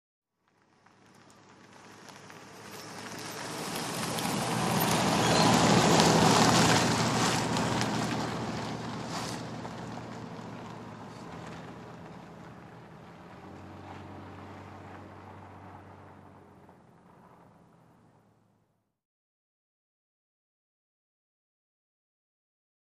Car Long In 10 Mph And Pass By Close Perspective Then Long Away On Dirt Road.